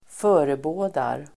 Uttal: [²f'ö:rebå:dar]